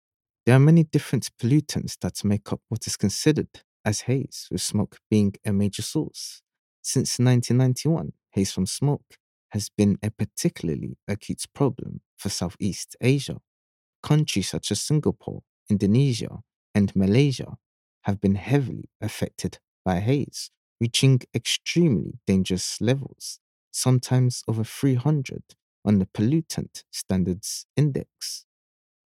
Voice Over Narration, Talent Artists & Actors
English (Caribbean)
Yng Adult (18-29) | Adult (30-50)